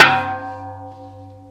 描述：这些是由锅，平底锅等炊具记录的声音......它们由金属和木棒制成。用Stagg PGT40 麦克风，Digidesignmbox（原始） 小型隔离室中的波形记录器。文件是 单声道，16,44khz.Processed with wave editor.Pack，设置“syd”。
Tag: 打击乐 SFX